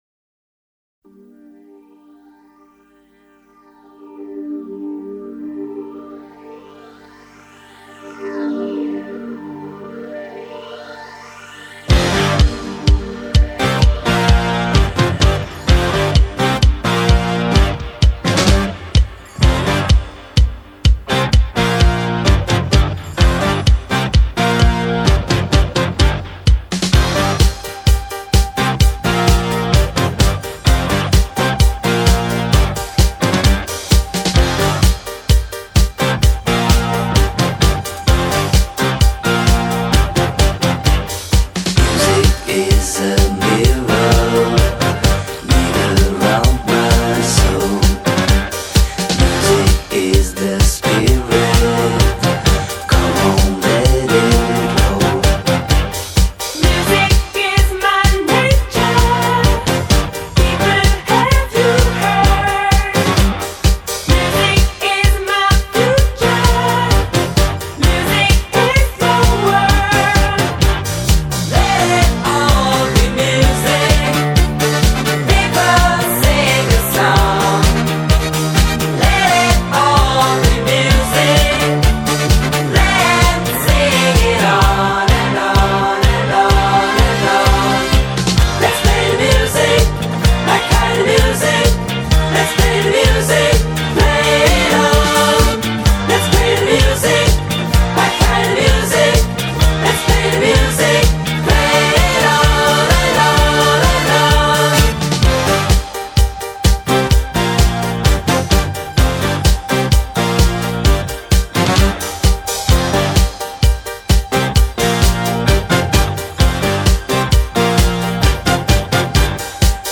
Disco, Pop